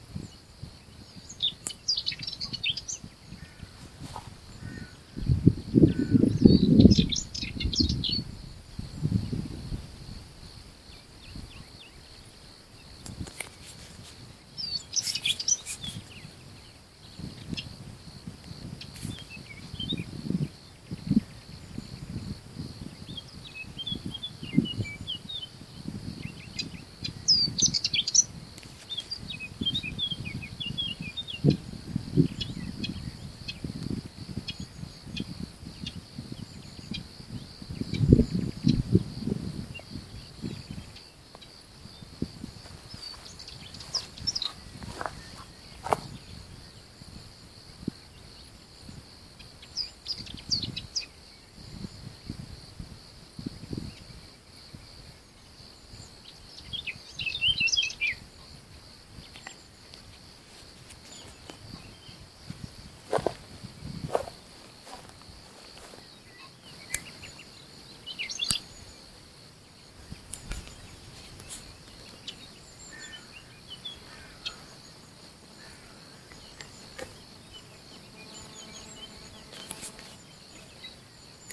Stavolta però ho qualche registrazione, un po' amatoriale ma rende l'idea.
Nella seconda si avvicina di più al classico dolce chack chack della cantillans.
Nella seconda mi sembra più cantillesco ma anche qui fa strofe brevi e 'scazzate', difficile valutare.